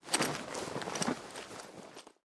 inv_close.ogg